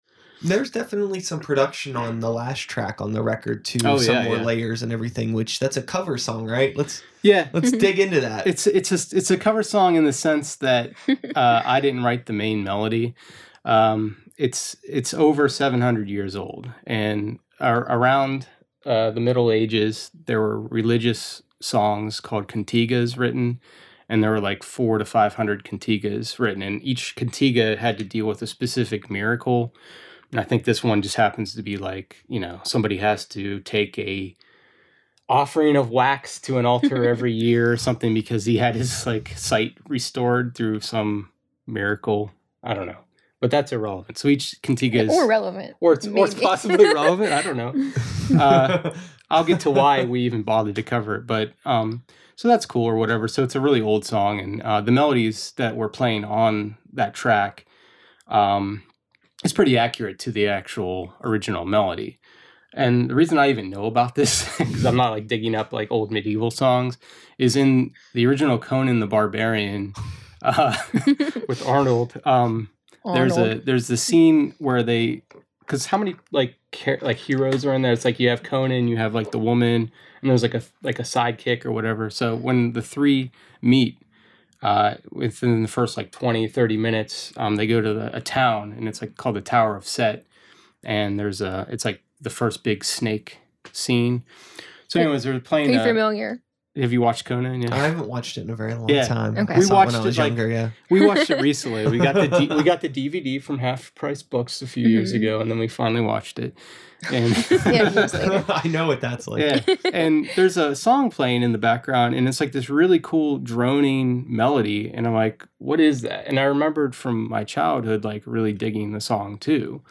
You’ll mostly just hear me giggling.